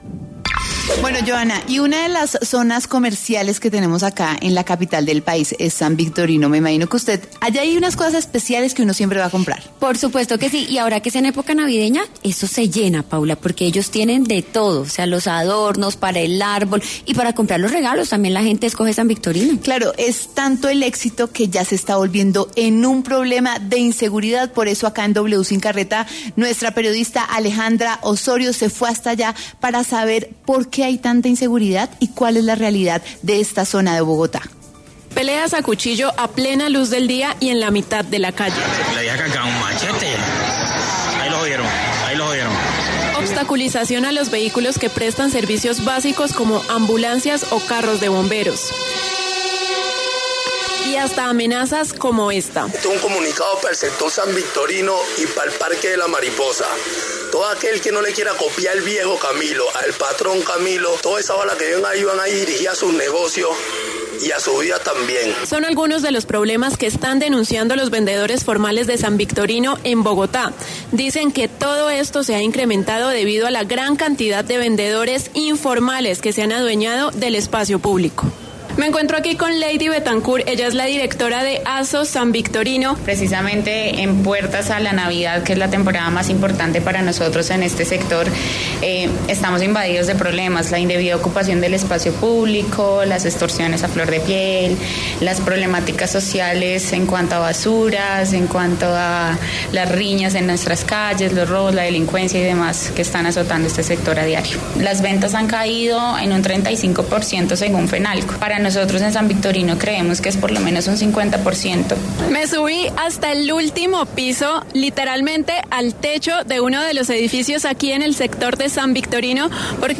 W Sin Carreta estuvo en San Victorino, un reconocido lugar de comercio en el centro de Bogotá, donde miles de personas realizan diariamente sus compras y que se caracteriza por tener una amplia variedad de productos, desde ropa hasta tecnología.